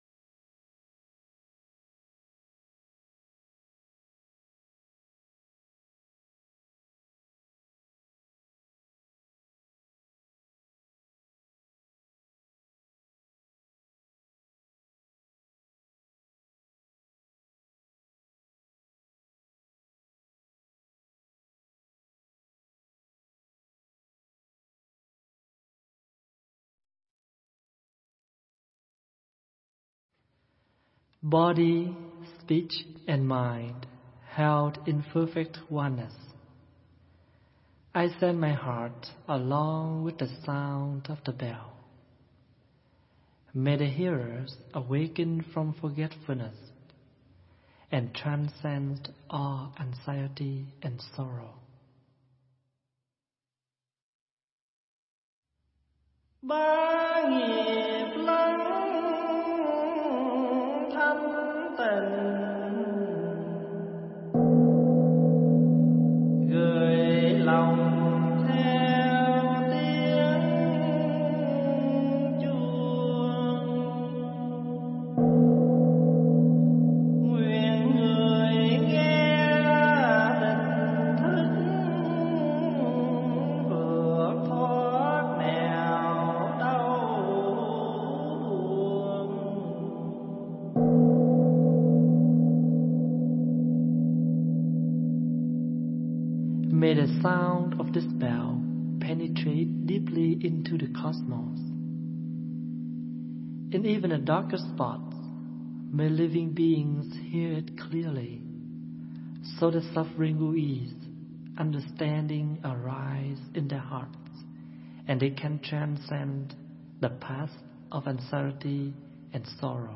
thuyết giảng tại Chùa Văn Thù (Riverside, Canada)